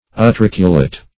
Search Result for " utriculate" : The Collaborative International Dictionary of English v.0.48: Utriculate \U*tric"u*late\, a. Resembling a bladder; swollen like a bladder; inflated; utricular.